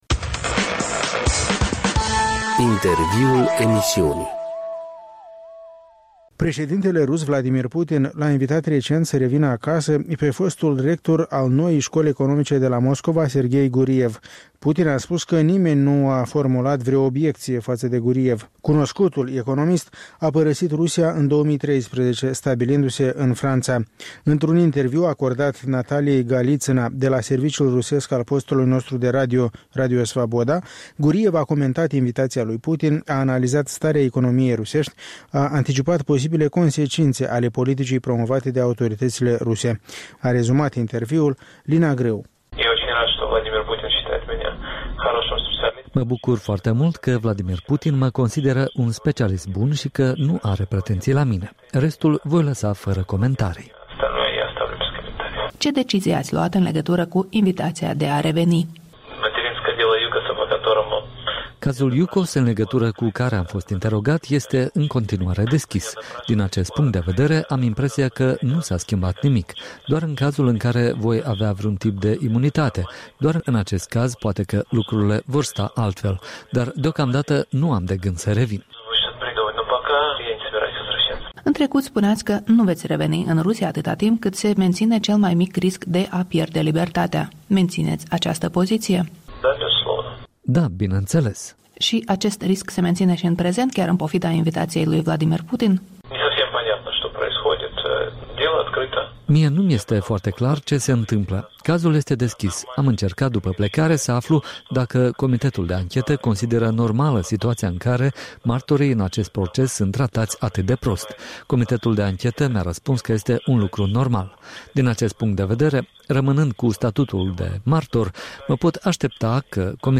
Un interviu cu economistul rus Serghei Guriev